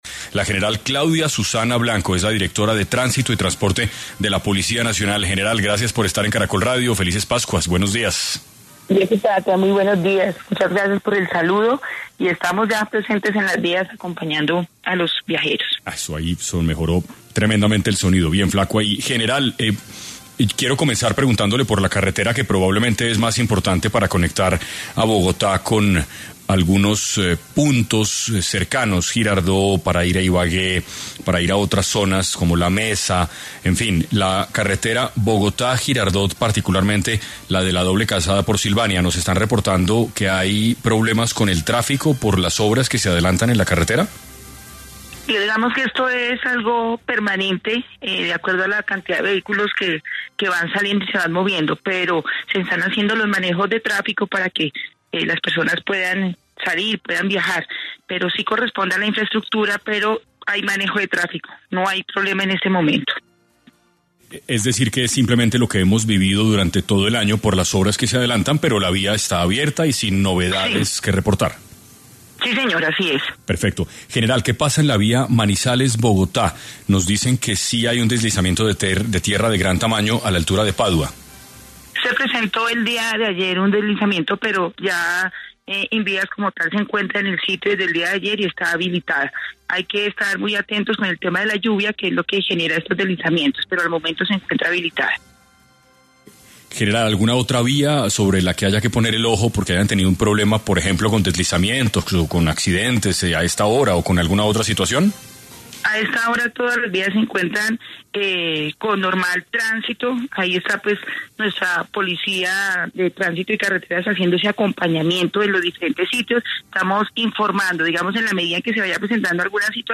La General Claudia Susana Blanco, directora de Tránsito y Transporte Policía Nacional, estuvo en ‘6AM’ de Caracol Radio y recomendó a los viajeros tener en cuenta el estado de algunos corredores viales.